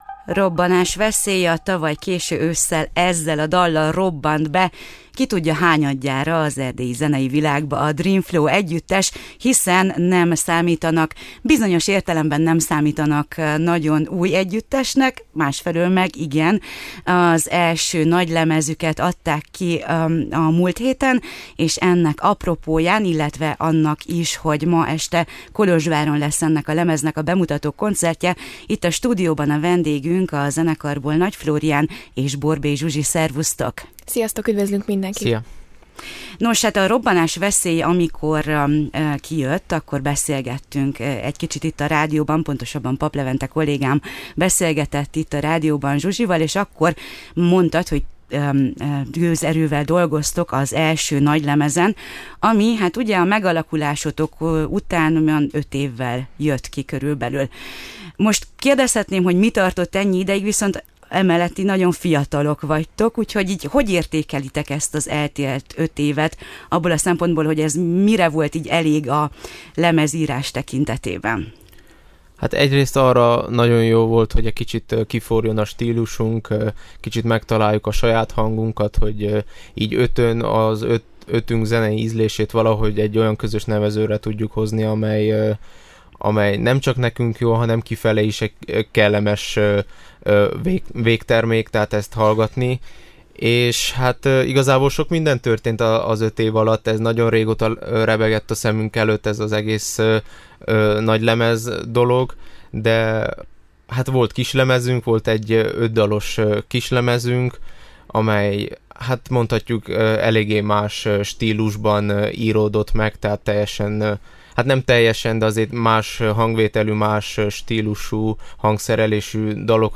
Csütörtökön mutatják be új albumukat Kolozsváron, előtte még a Rock and Roll FM vendégei voltak.